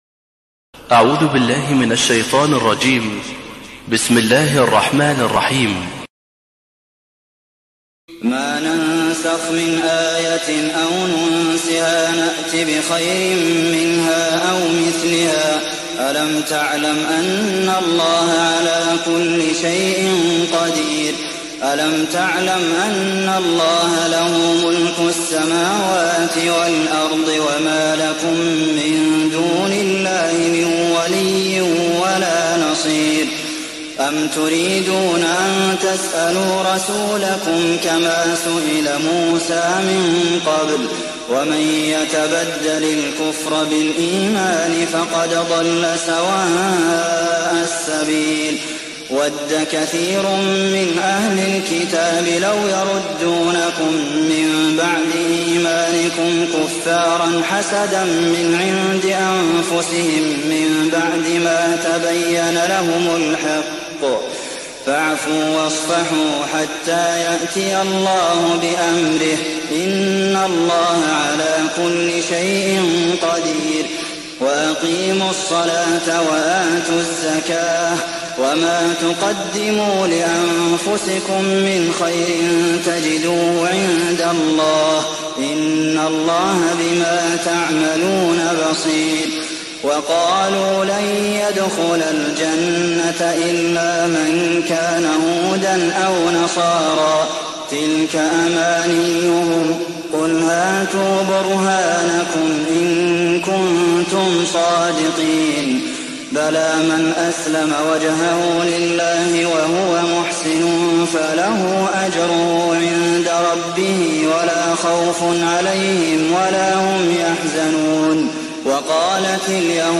تهجد ليلة 21 رمضان 1419هـ من سورة البقرة (106-176) Tahajjud 21st night Ramadan 1419H from Surah Al-Baqara > تراويح الحرم النبوي عام 1419 🕌 > التراويح - تلاوات الحرمين